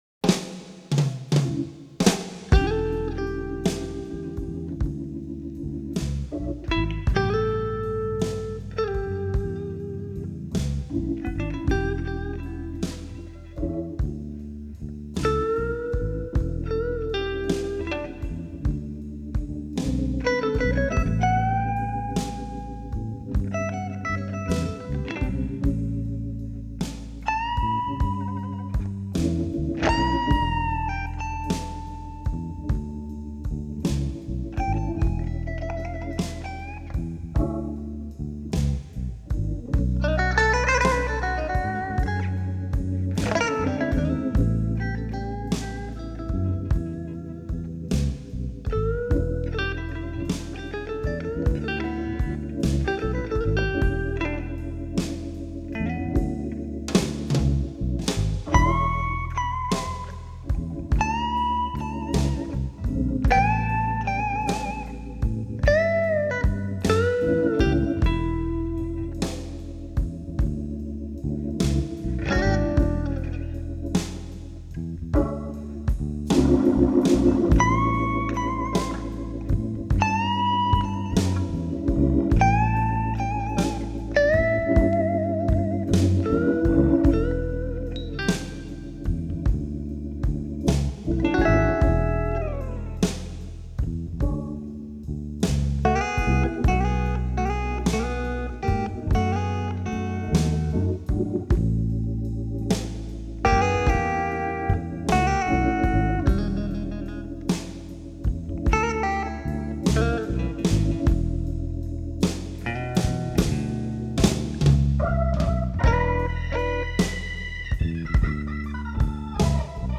• Категория:Лирический блюз